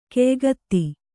♪ keygatti